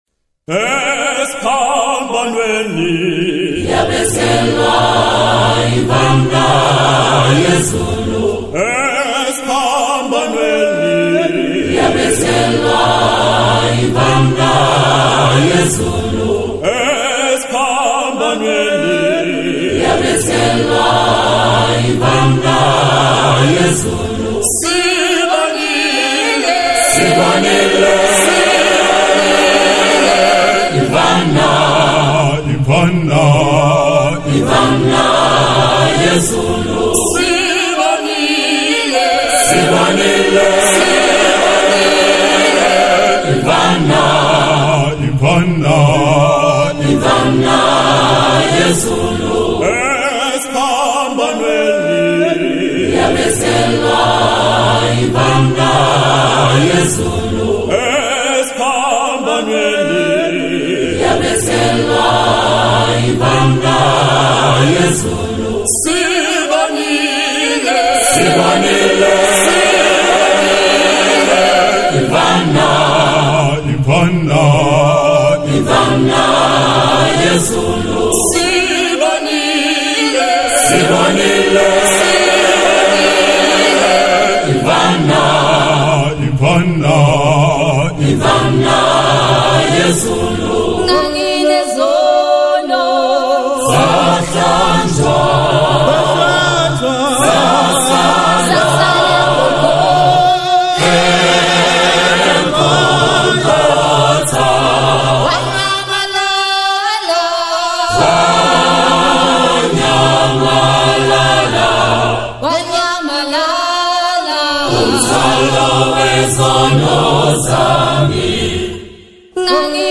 The song has four verses and a chorus.